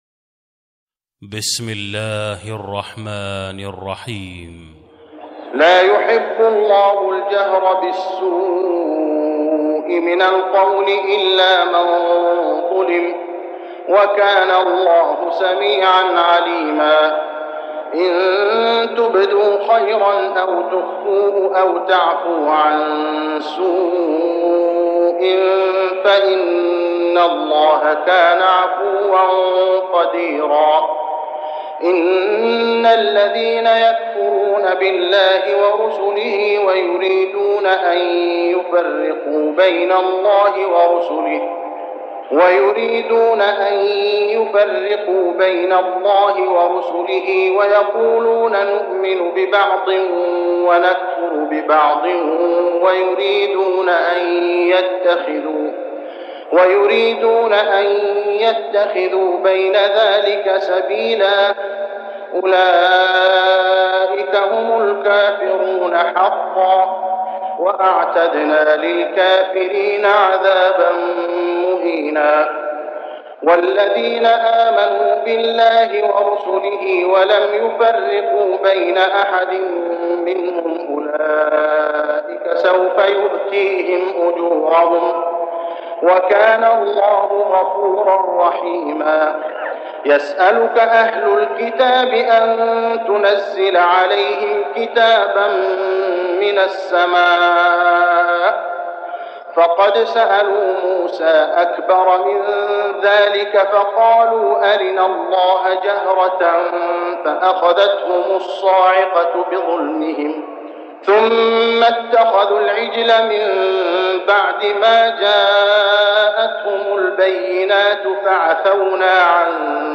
صلاة التراويح ليلة 7-9-1410هـ سورتي النساء 148-176 و المائدة 1-26 | Tarawih prayer Surah An-Nisa and Al-Ma'idah > تراويح الحرم المكي عام 1410 🕋 > التراويح - تلاوات الحرمين